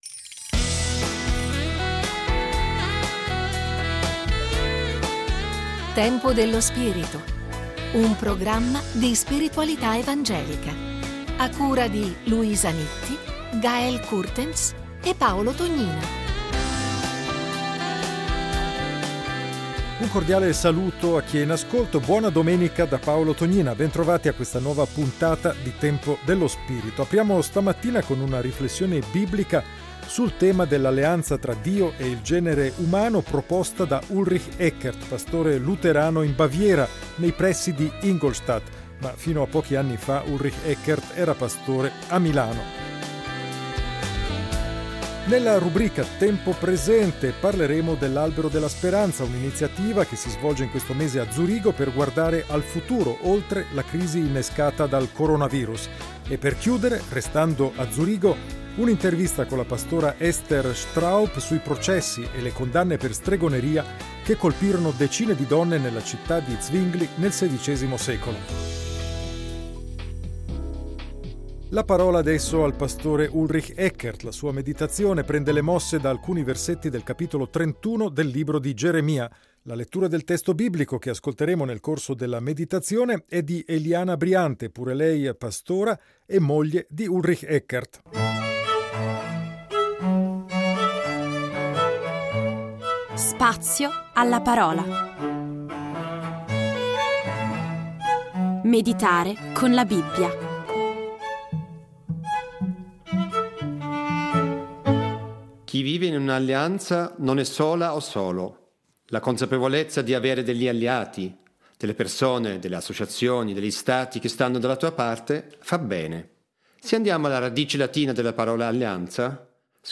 Il Salmo 104 in musica: un inno all’alleanza di Dio con la creazione.
Intervista .